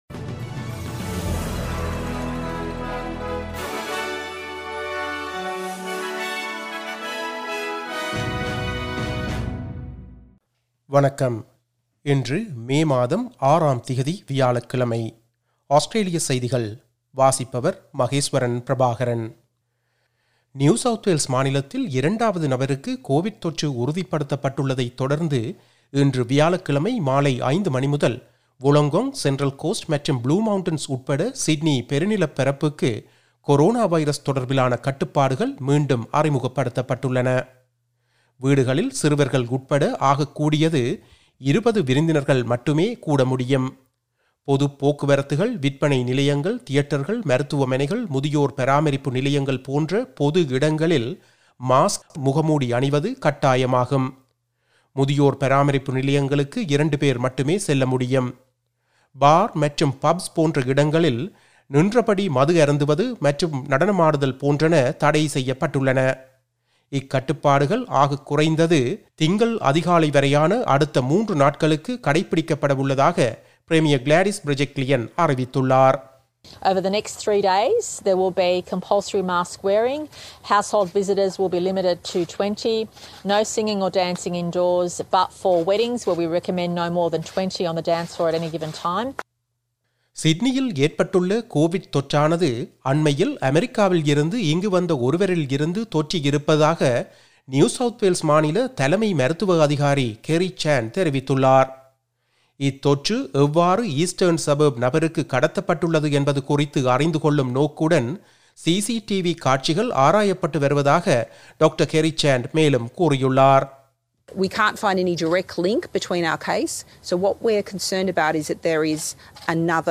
Australian news bulletin for Thursday 06 May 2021.